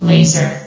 CitadelStationBot df15bbe0f0 [MIRROR] New & Fixed AI VOX Sound Files ( #6003 ) ...